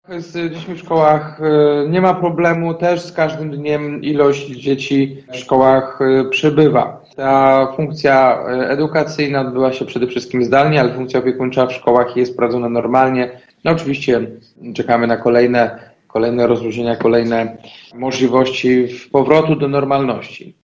Obecną sytuację w mieście przekazał dziś gorzowskim radnym prezydent Jacek Wójcicki: